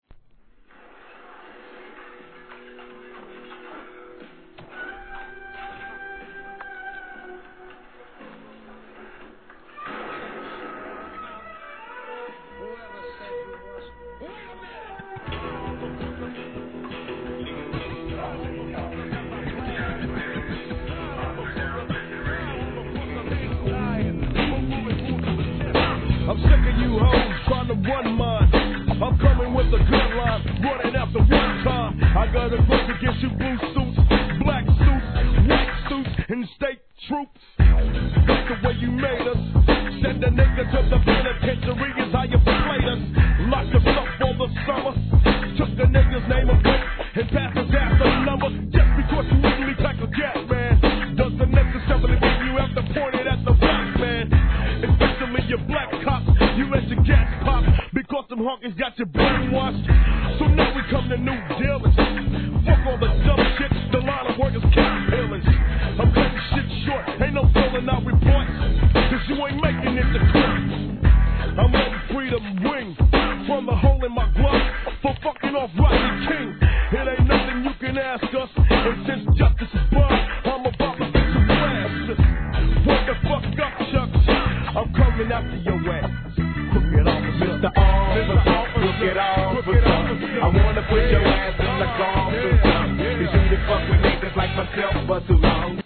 G-RAP/WEST COAST/SOUTH
ミディアムFUNKで聴かせるCOOLな一曲!!